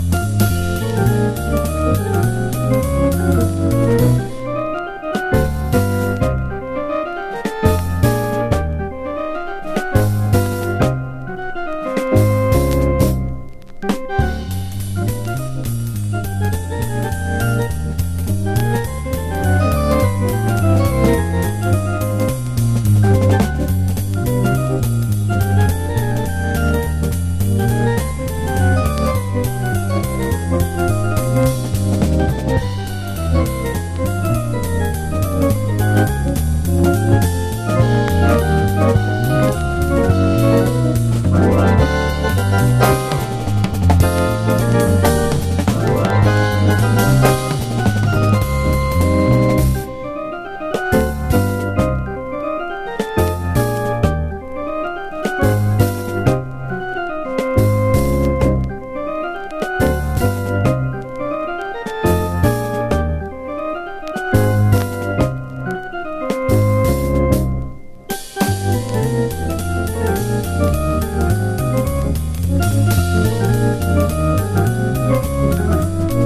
JAZZ / JAPANESE / DRUM / BIG BAND
ブリッと分厚いブラスが主役で